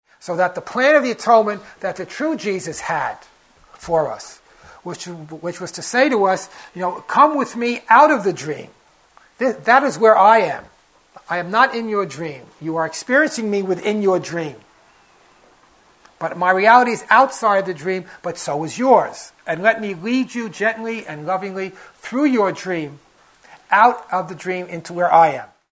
Original Workshop Date: 06/1996